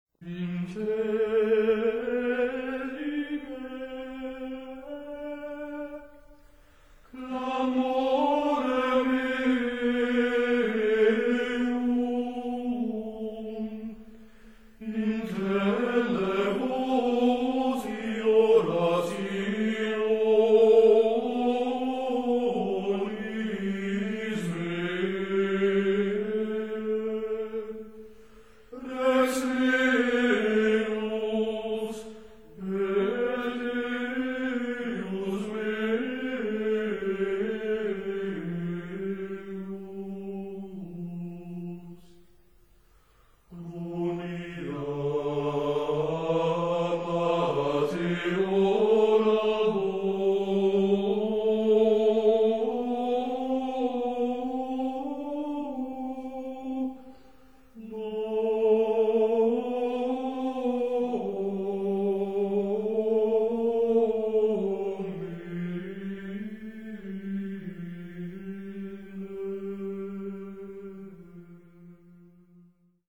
Choralschola der Wiener Hofburgkapelle – Gregorian Chants For All Seasons [2 CD]
Григорианские песнопения годичного богослужебного круга в исполнении школы хорала при Венской придворной капелле Хофбурга (Австрия). 1990 г.